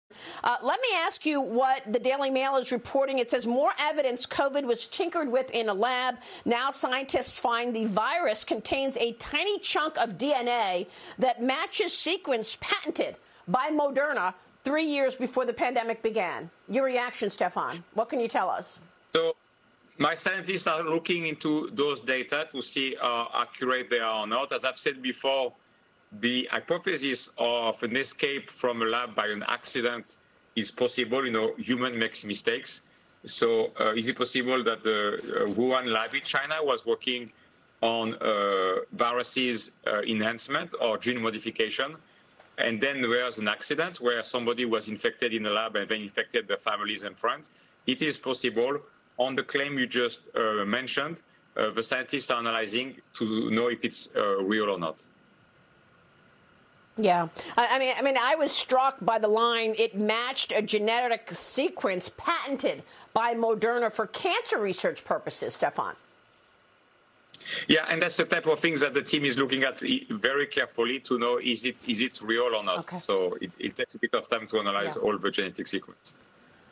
ראיון בפוקס ניוז: מדענים גילו כי הוירוס מכיל פיסת די.אן.איי התואמת לרצף גנטי המוגן בפטנט עי חברת מודרנה